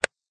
click_hard_wood.ogg